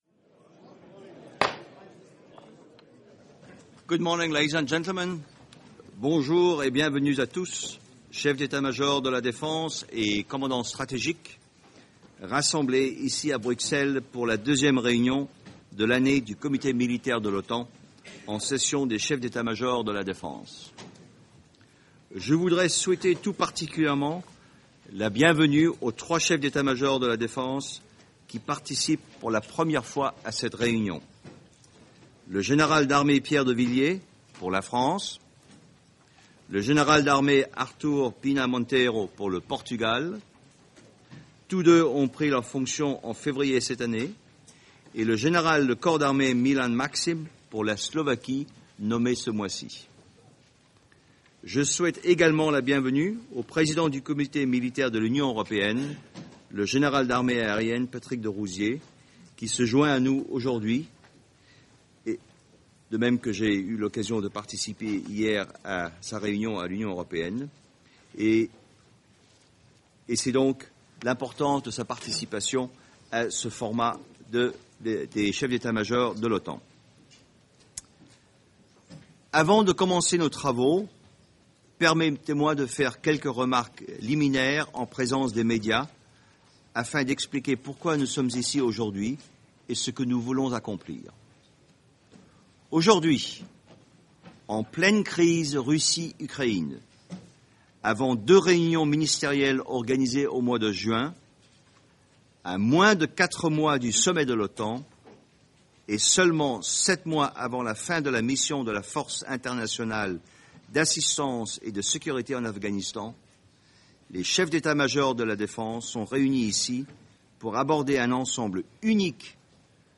Opening remarks by the Chairman of the Military Committee, General Knud Bartels, at 171st meeting of the Military Committee in Chiefs of Staff session